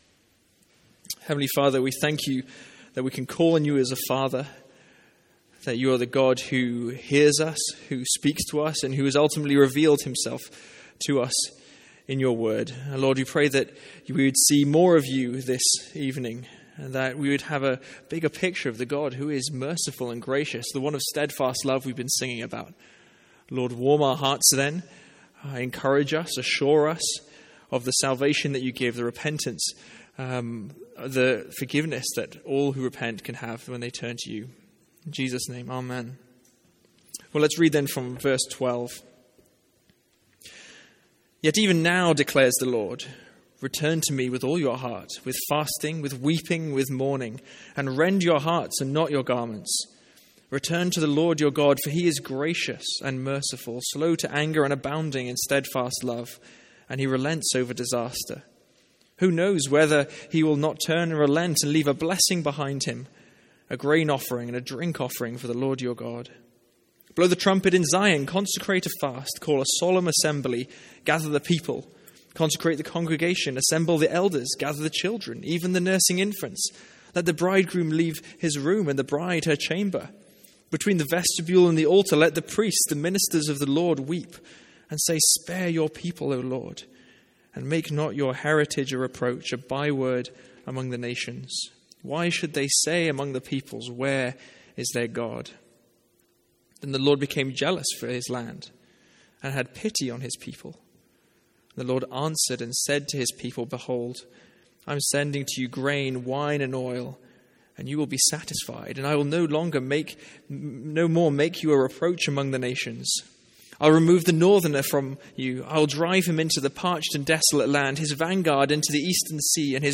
Sermons | St Andrews Free Church
From our evening series in Joel.